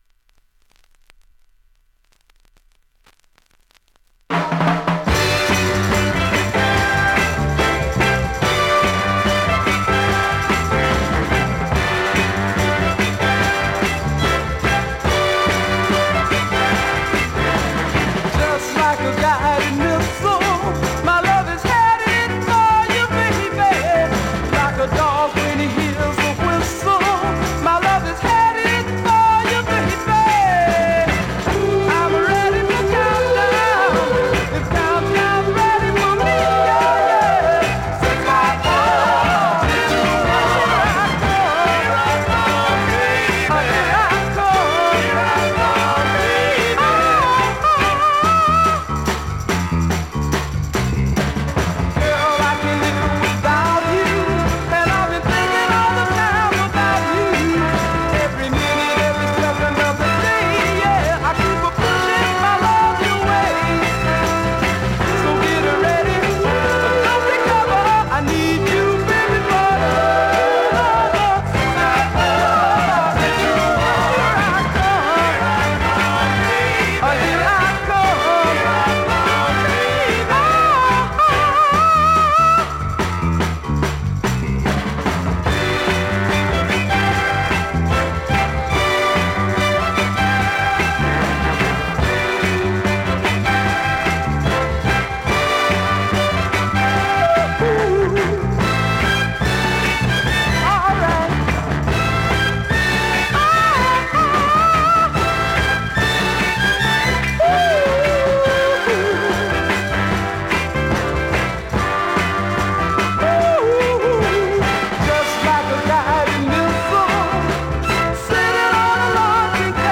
現物の試聴（両面すべて録音時間５分２７秒）できます。